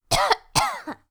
cough1.wav